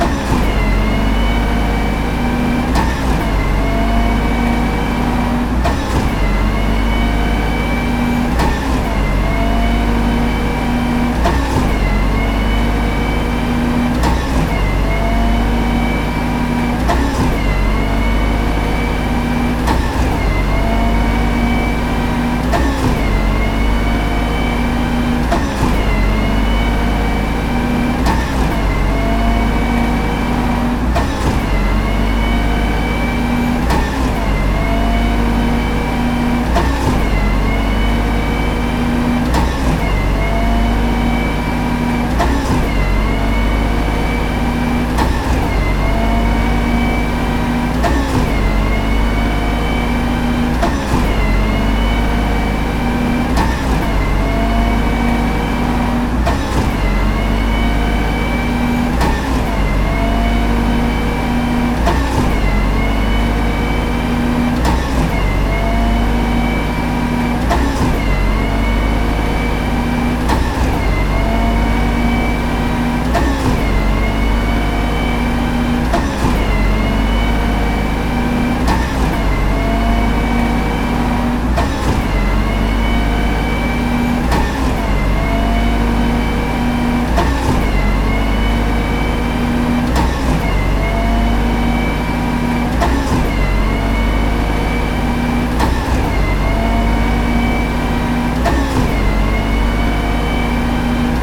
街の環境音２（騒音系）
工事現場
生コンクリート注入
pumping_concrete　DL
pumping_concrete.mp3